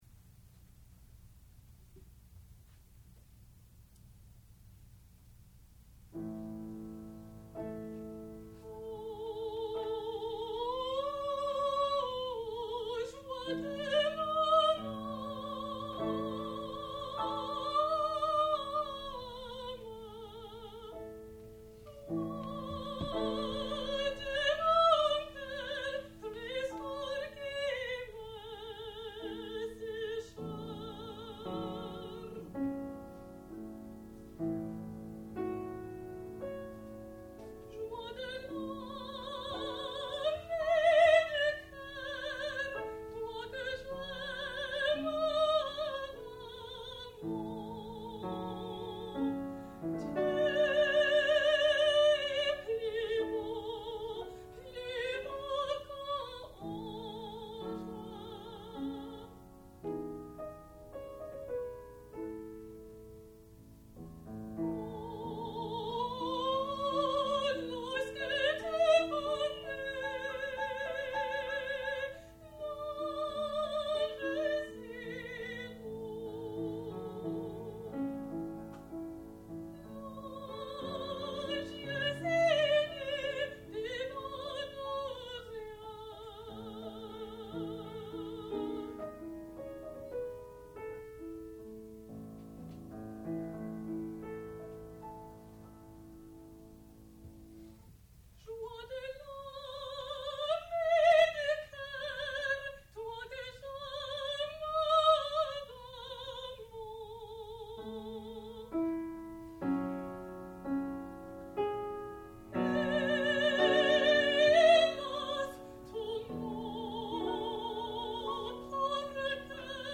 sound recording-musical
classical music
piano
Master's Recital
mezzo-soprano